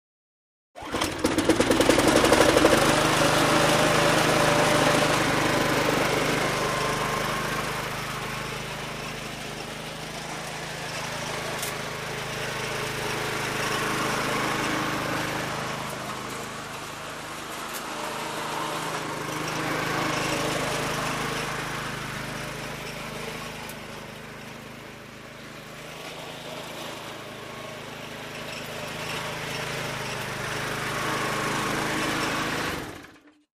Lawnmowers
in_lawnmower_mowing_01_hpx
Lawn mower starts and mows from left to right then shuts off with engine idles and pulls.